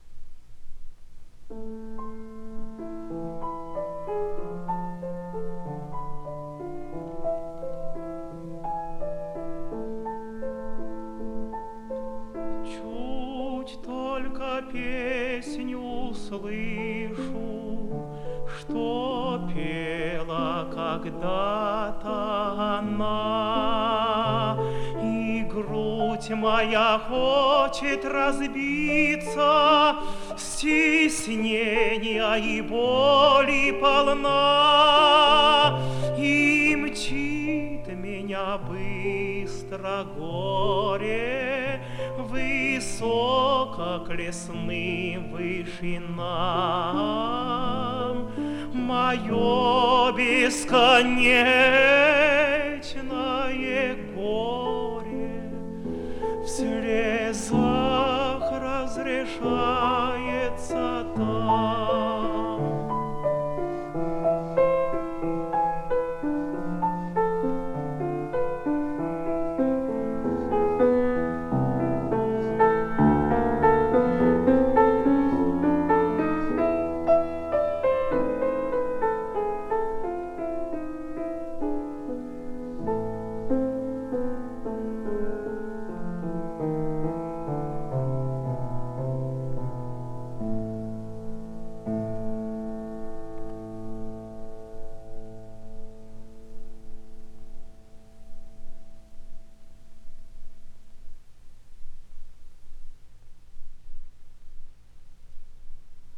Вокальный цикл